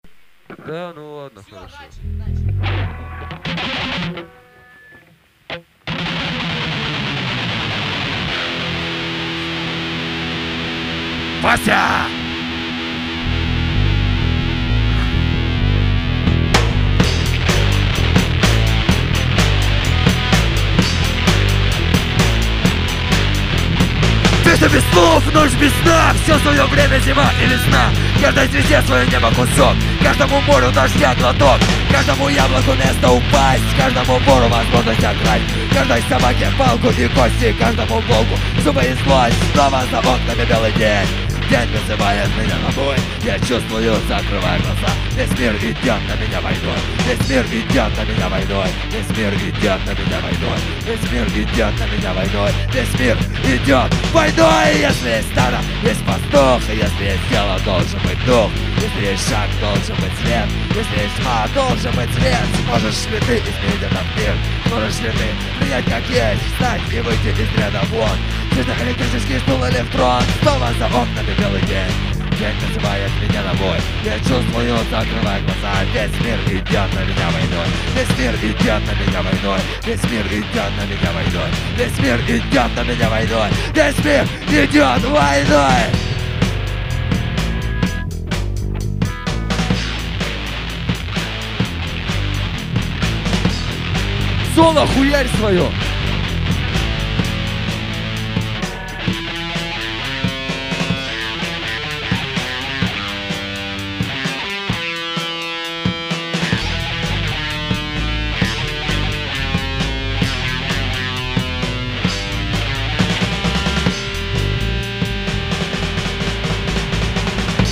- Мы играем хэви и альтернативу (грызет ногти)
ремикс